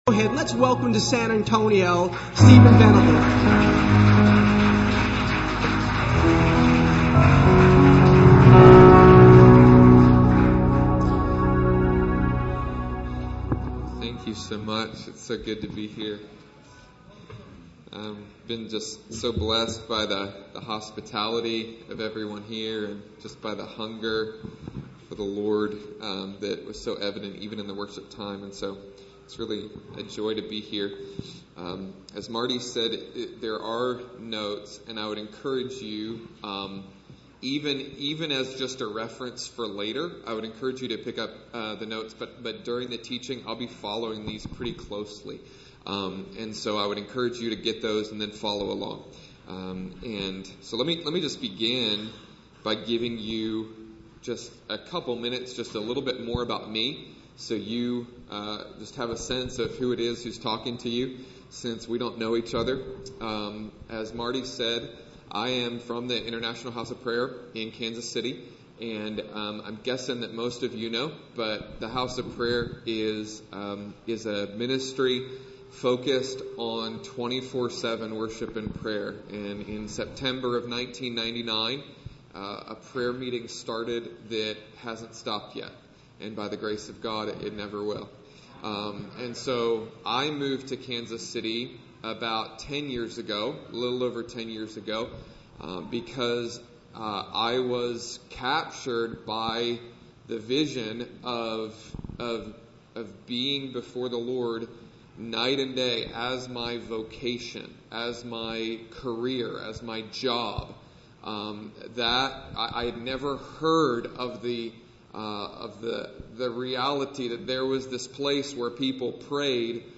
In this opening message from the Key of David Prayer Conference held at Ethnos Missions Center